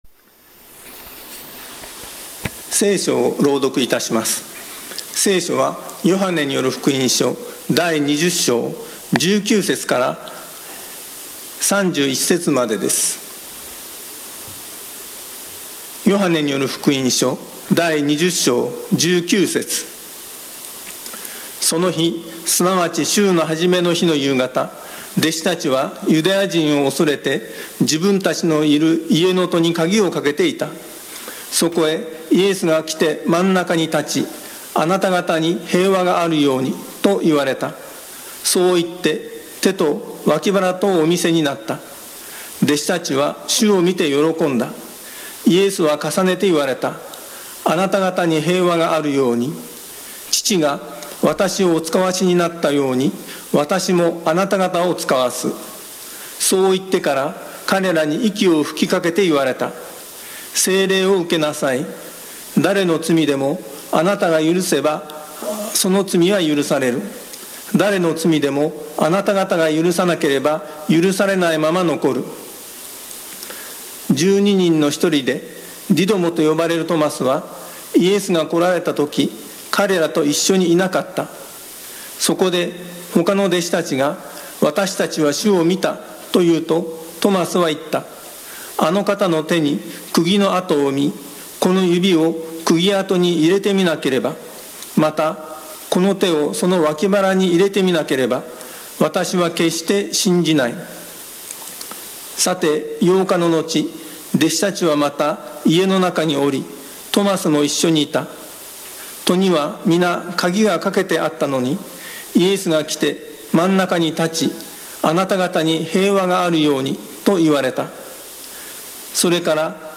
毎週日曜日に行われ礼拝説教のアーカイブデータです。
Youtubeで直接視聴する 音声ファイル 礼拝説教を録音した音声ファイルを公開しています。